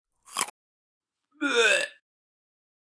modervomitar.ogg